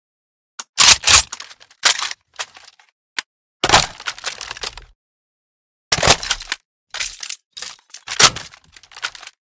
reload_empty.ogg